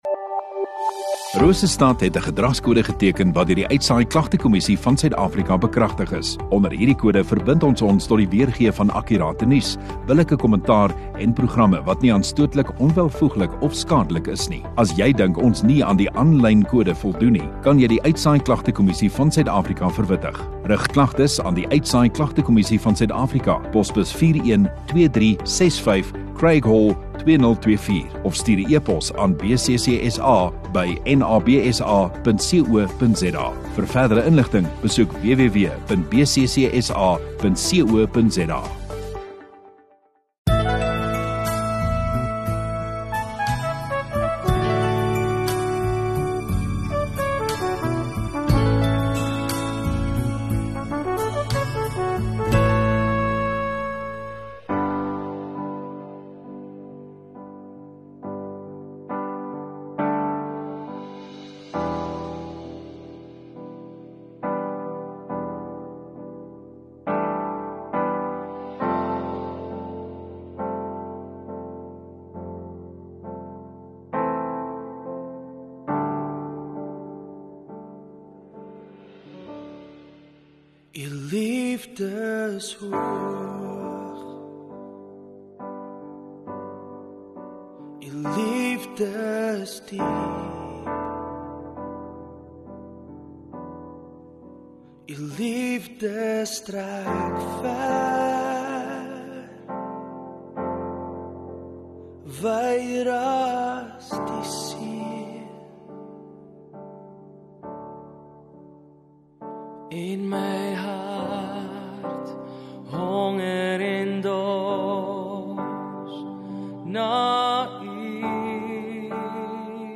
4 Aug Sondagaand Erediens